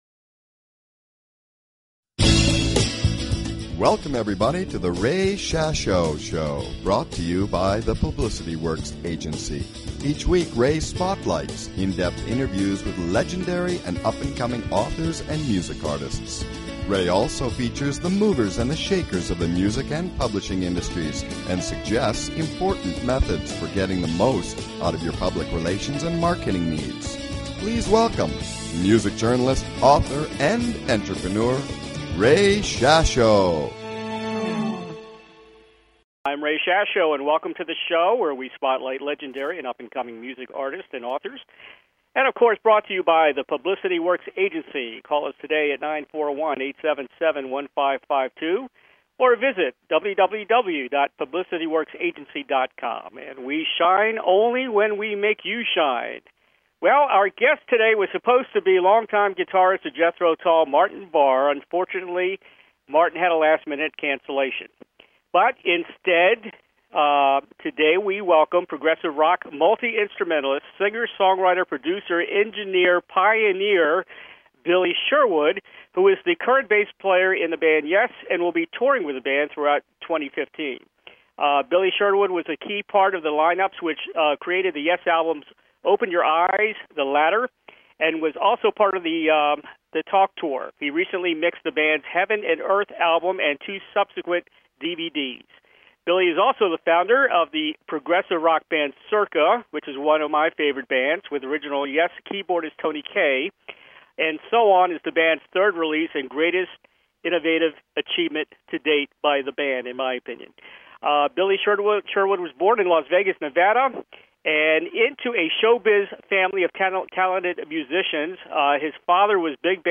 Guest, Billy Sherwood